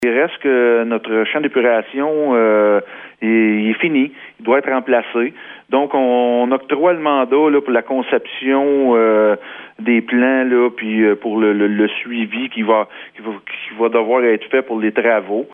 Le maire, Mathieu Caron, donne les détails :